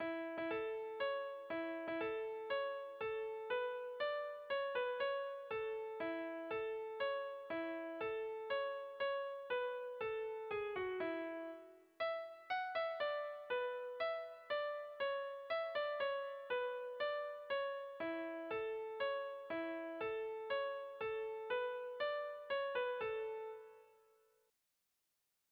Irrizkoa
Zortziko txikia (hg) / Lau puntuko txikia (ip)
A-A2-B-A3